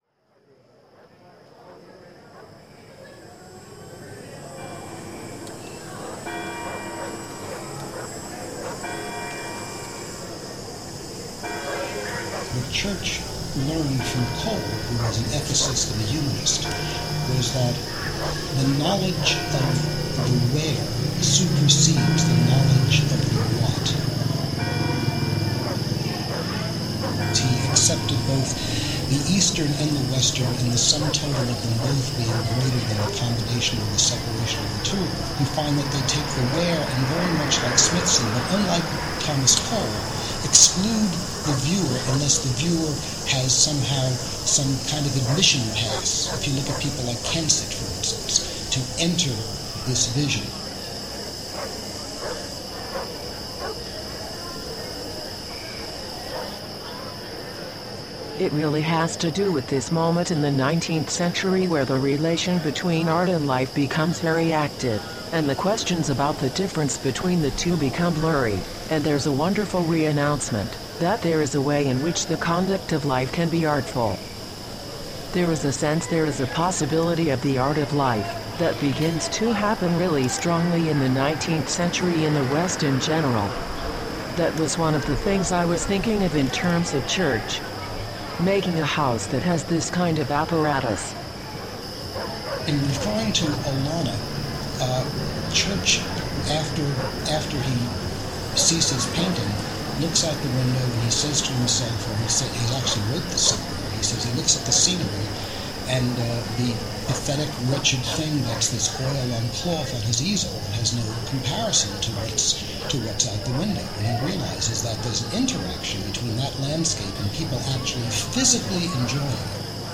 Entervision was presented as a radio transmission, broadcast from Church's home.
Entervision gathered field recordings from nature and human-made sources collected from the visible surroundings of Olana: The recordings are interspersed with excerpted phrases from conversations staged to address and interpret aesthetic and philosphical issues generate